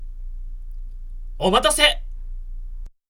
ボイス
ダウンロード 男性_「お待たせっ」
大人男性